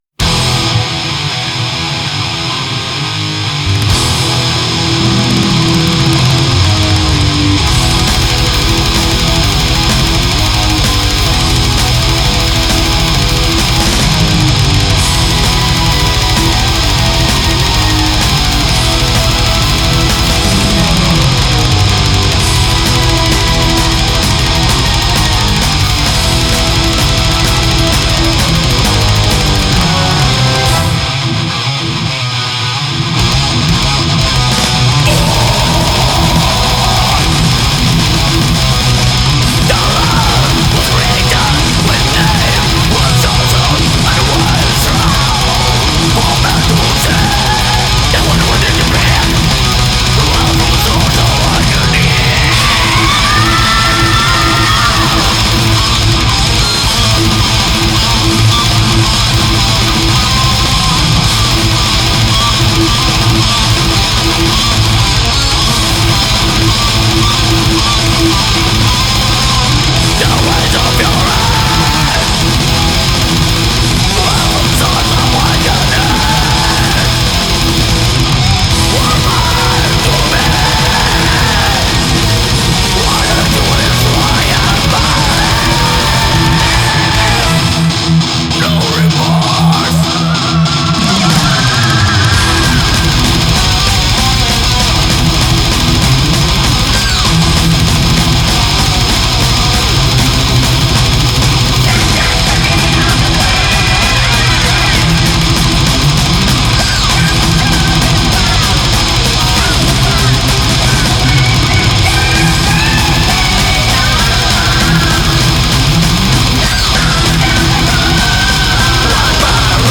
genres: black metal lyrics